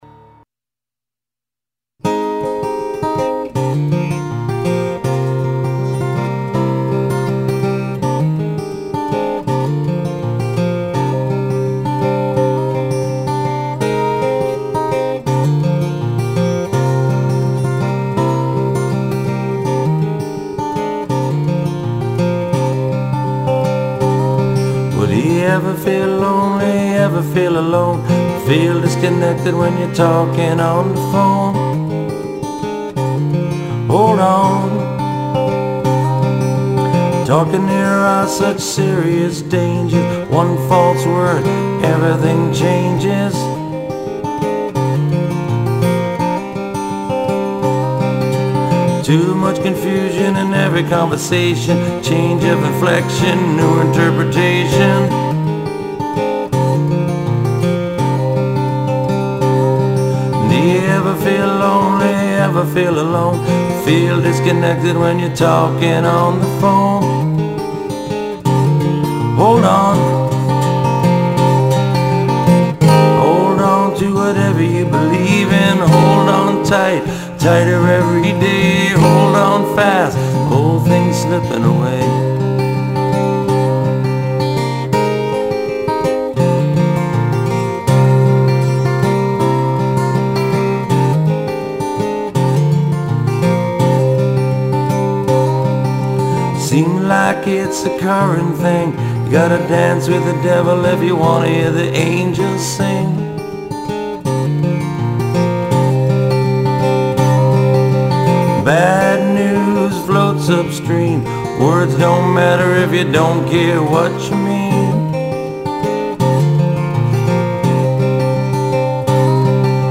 All solo performances